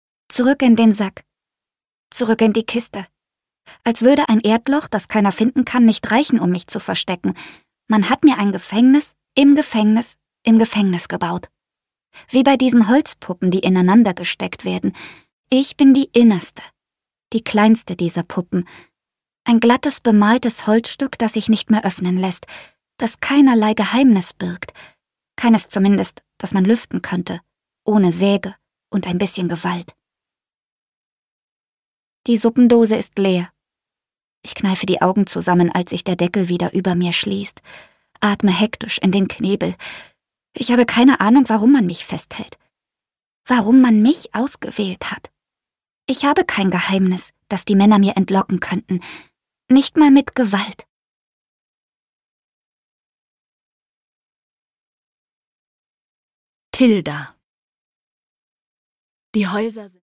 Vera Buck: Der dunkle Sommer (Ungekürzte Lesung)
Produkttyp: Hörbuch-Download